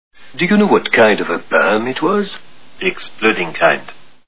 Pink Panther Movies Movie Sound Bites